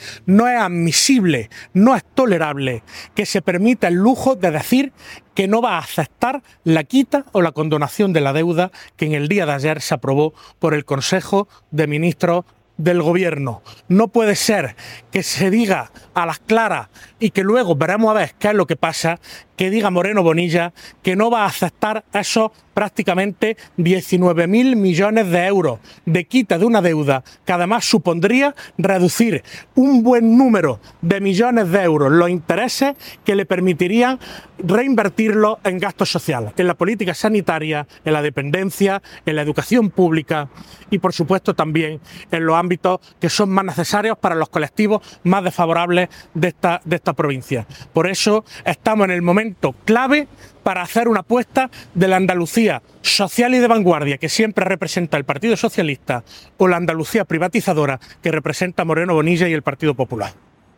Cortes de sonido # Víctor Torres